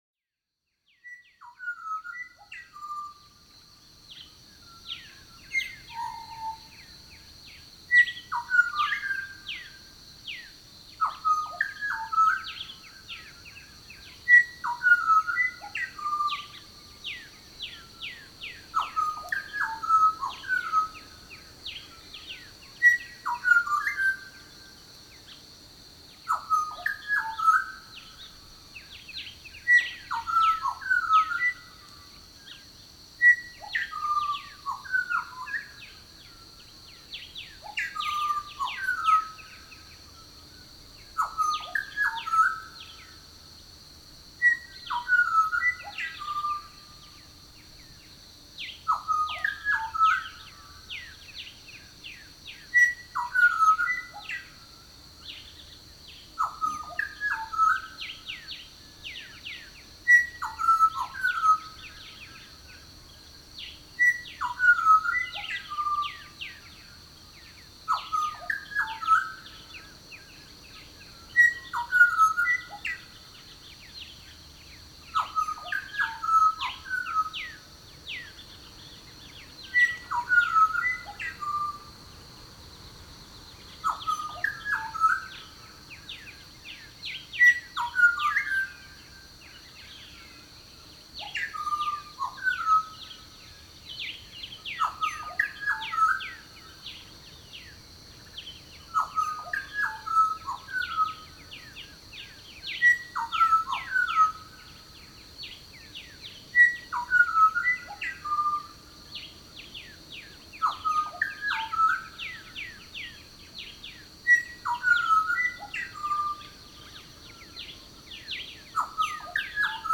6:30am…Pied Butcher Bird Songcycle – NT
We set up camp off the beaten track & by a lake … somewhere in Central Northern Territory.
Early one morning we were visited by this remarkable Pied Butcherbird. He (?) perched somewhere above us & began this repeating but everchanging songcycle. It went for over 30 minutes.
Pied Butcherbirds have a captivating song-cycle … far more striking than other butcherbirds.
r05_butcherbird-songcycle_6-30am-lakeside-short.mp3